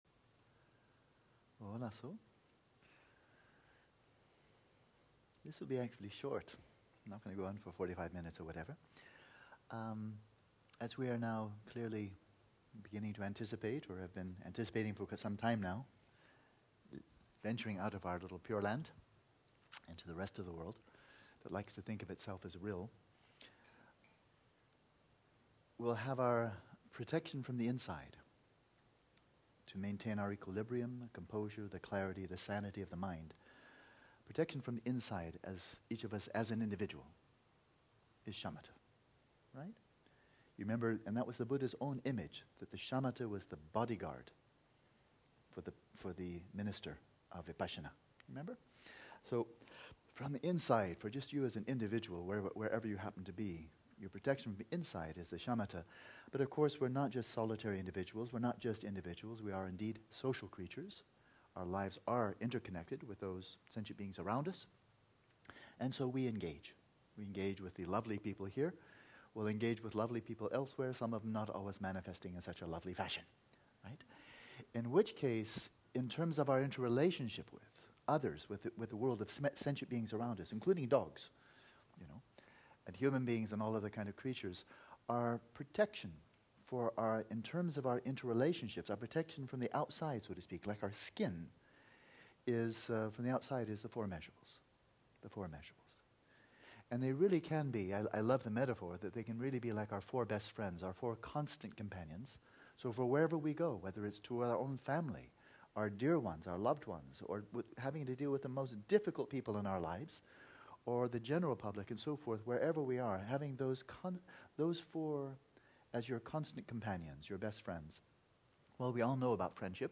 The session continued with a free meditation, and ended with 5 very interesting questions and answers.